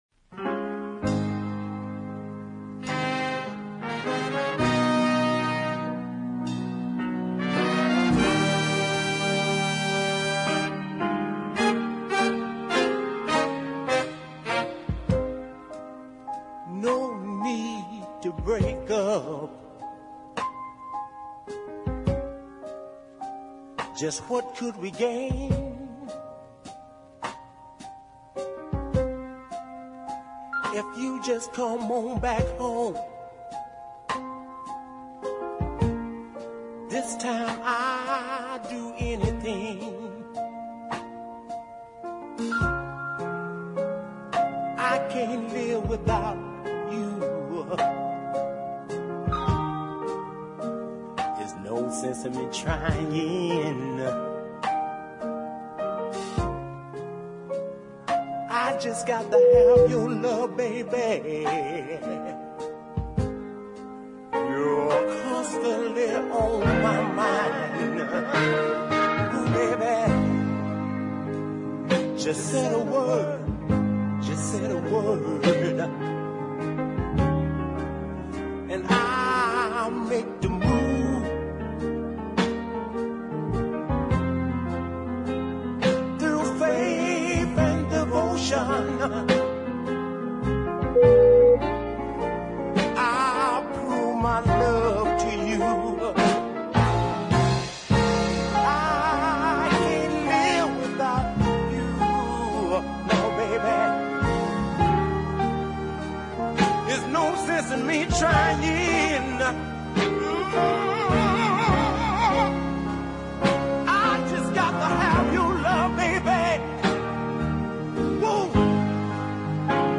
horns
Love his doubled tracked wailing as well.